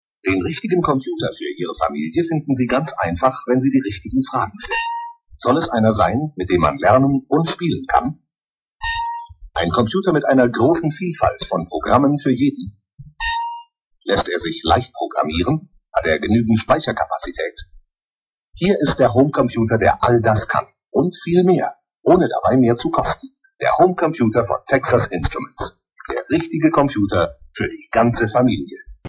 Texas Instruments German Audio Commercial
texas_instruments_commercial.mp3